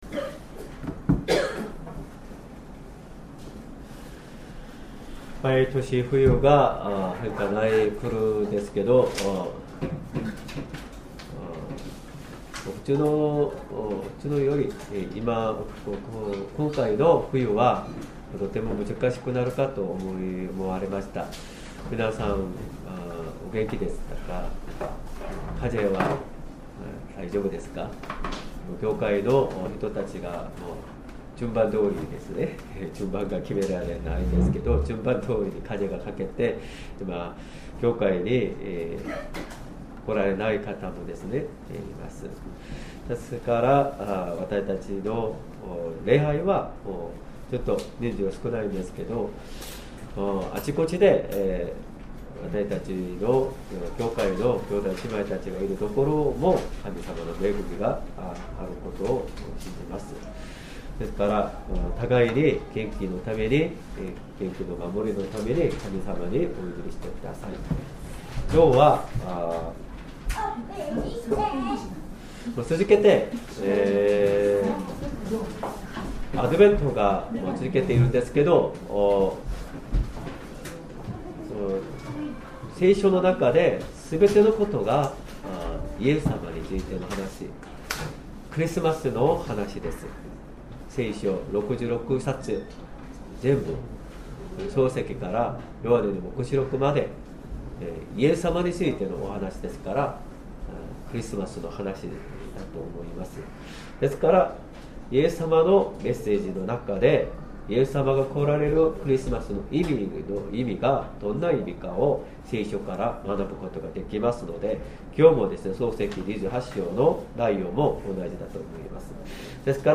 Sermon
2025年12月14日 主日礼拝 説教 「まことに主はこの場所におられる」 聖書 創世記28章10節～22節 28:10 ヤコブはベエル・シェバを出て、ハランへと向かった。